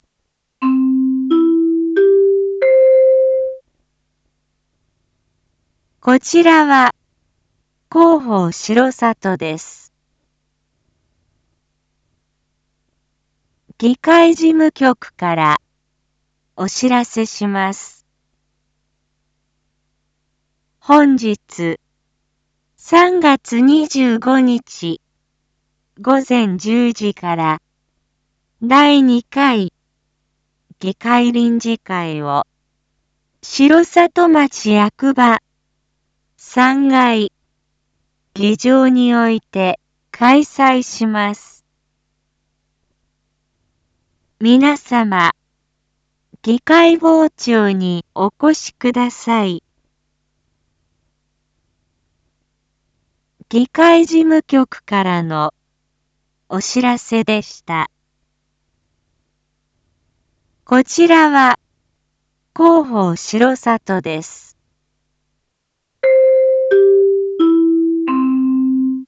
Back Home 一般放送情報 音声放送 再生 一般放送情報 登録日時：2026-03-25 07:01:11 タイトル：R8.3.25 第２回議会臨時会② インフォメーション：こちらは広報しろさとです。